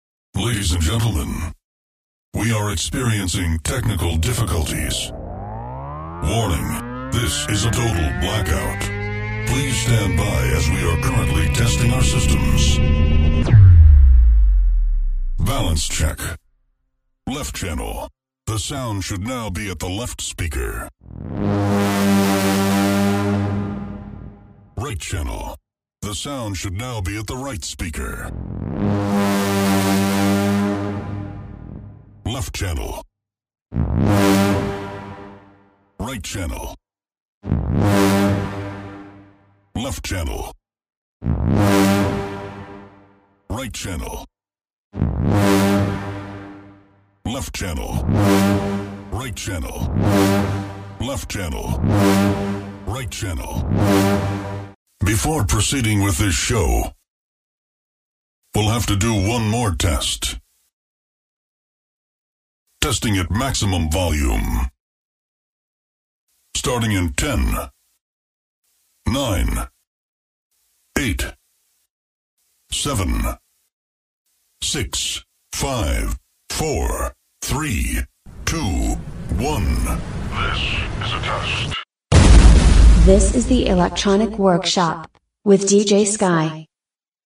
the electronic Werkshop air check